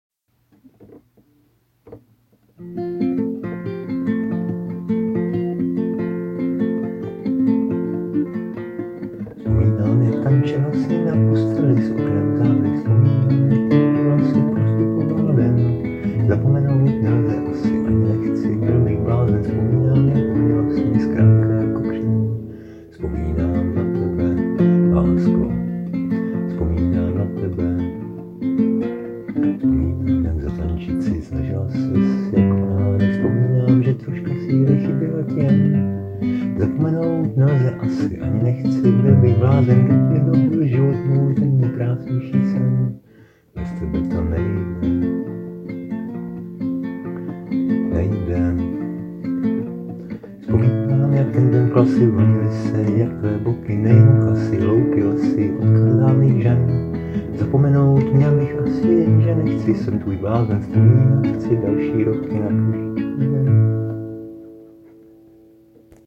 Básně » Romantické
romantika rozehrála strunky na kytaře, ať třebas fikce, ale bylo fajn si to poslechnout :-)*